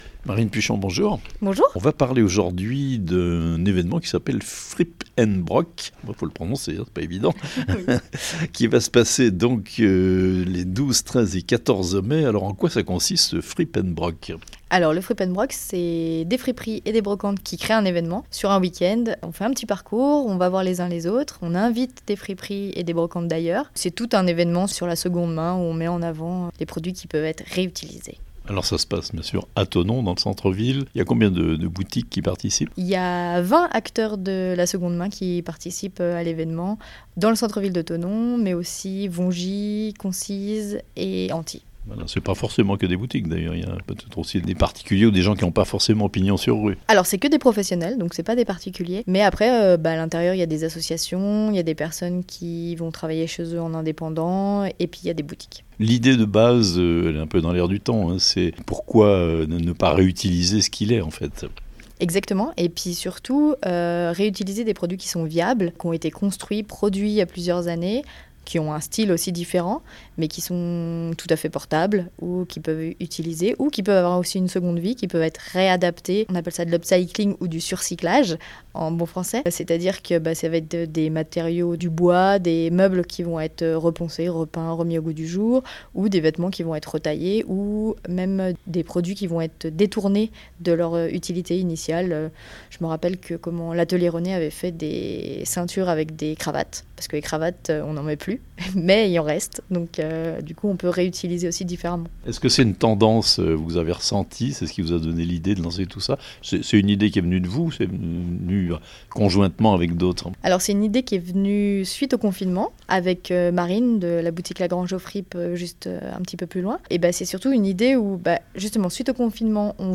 "Le Frip'N'Broc Tour", le festival de la seconde main se déroulera du 12 au 14 mai prochains à Thonon (interview)